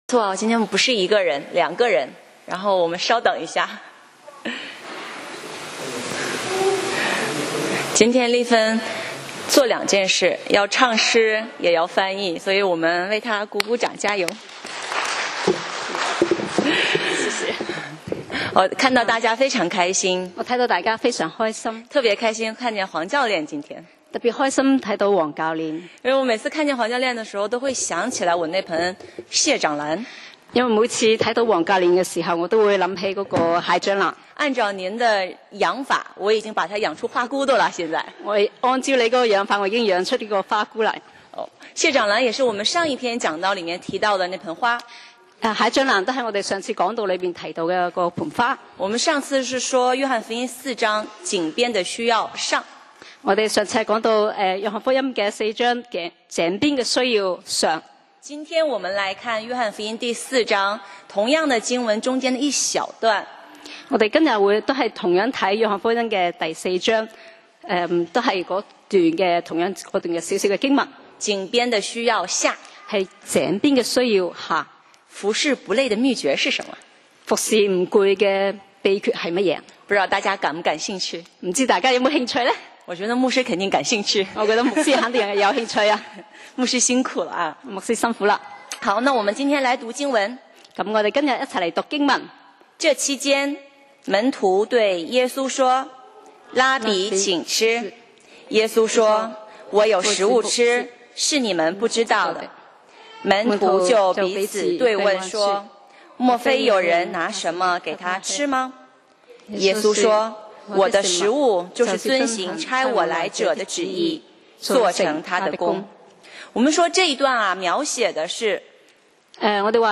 講道 Sermon 題目 Topic：井边的需要（下)—服事不累的秘诀 經文 Verses：约翰福音4:31-34.31这其间，门徒对耶稣说，拉比请吃。